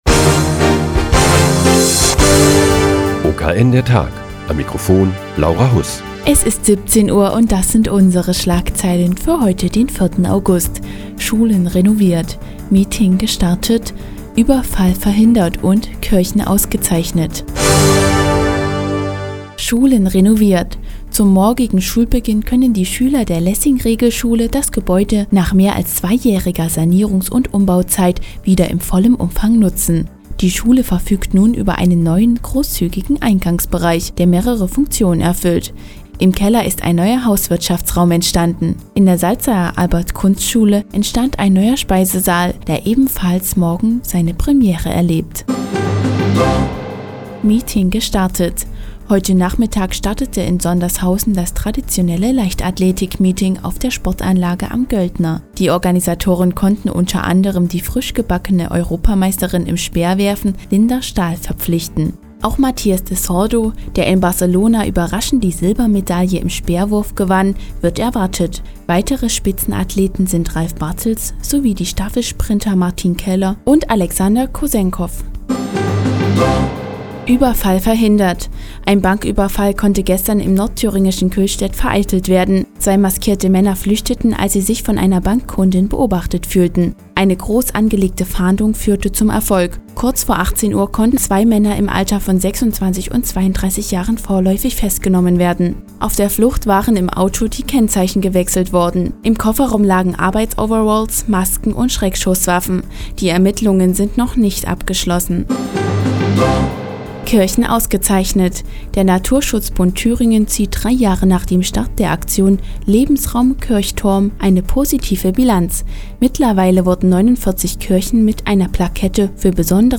Die tägliche Nachrichtensendung des OKN ist nun auch in der nnz zu hören. Heute geht es um die Beendigung der Sanierungsarbeiten an der Lessing- Regelschule und das traditionelle Leichtathletik- Meeting auf der Sportanlage am Göldner.